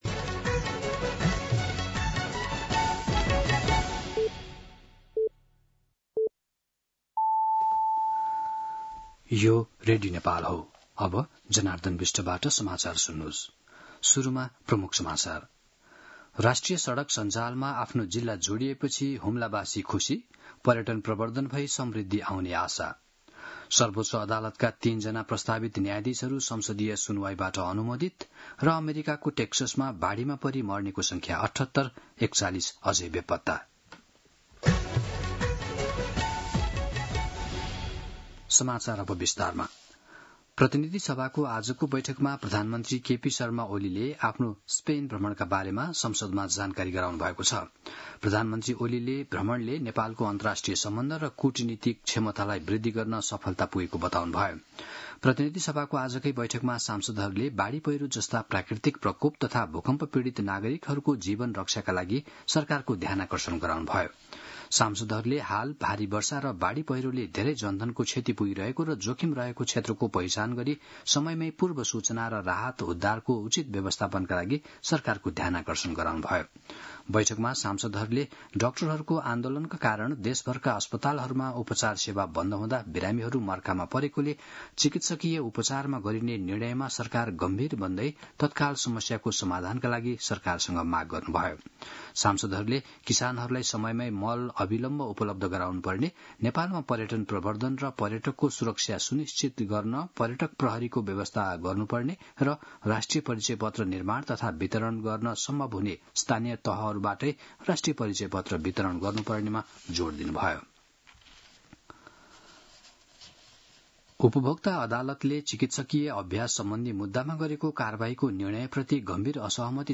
दिउँसो ३ बजेको नेपाली समाचार : २३ असार , २०८२
3-pm-News-3-23.mp3